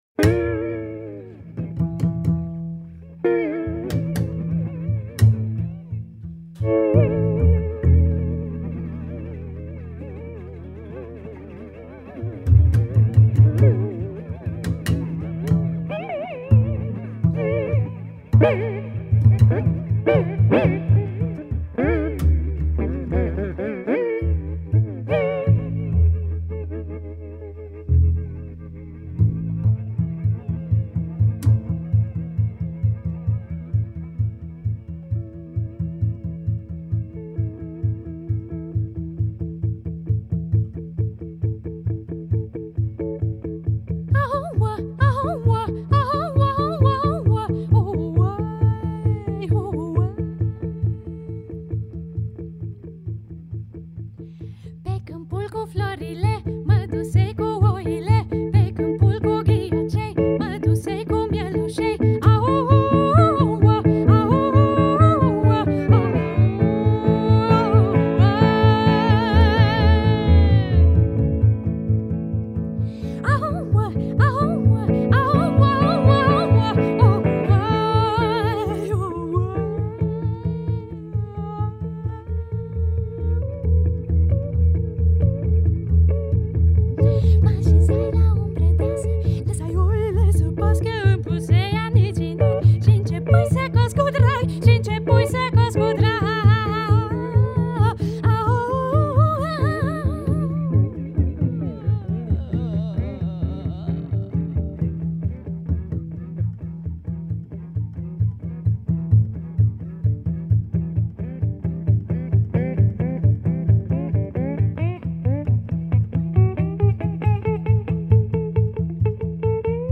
Vocal / Folk / Experimental.